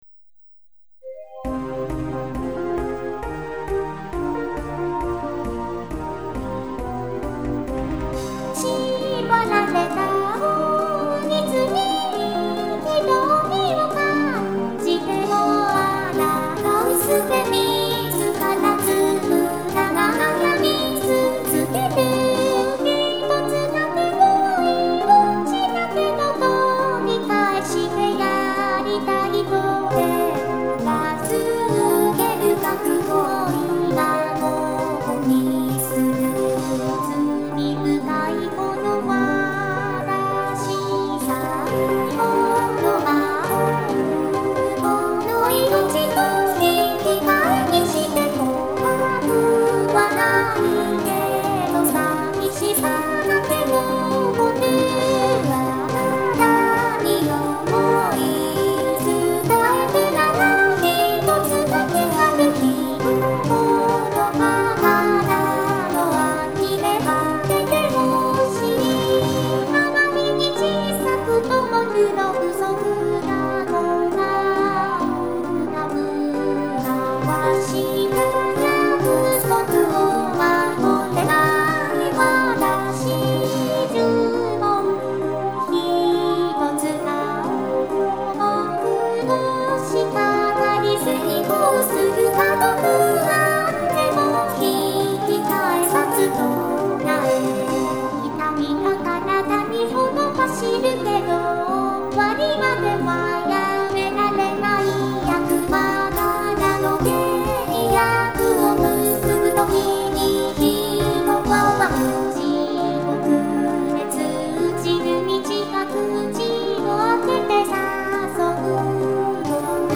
※創作ボーカロイドです。
〜ボーカル版〜